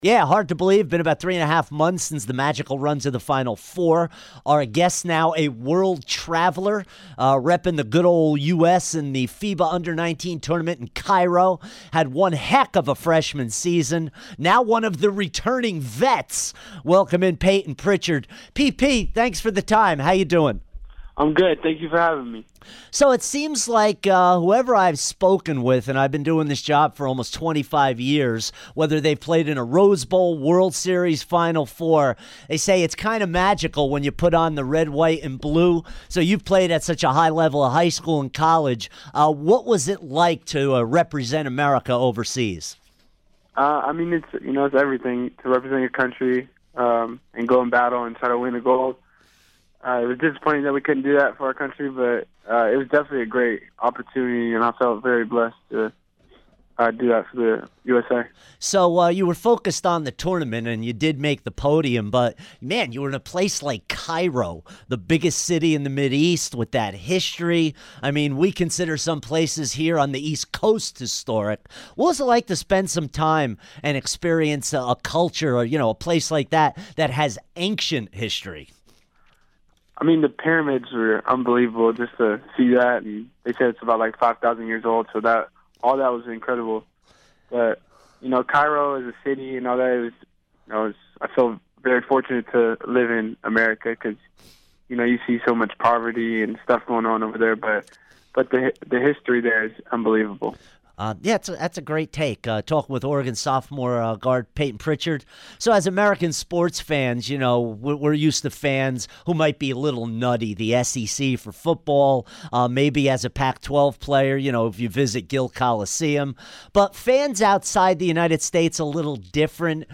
Payton Pritchard Interview 7-12-17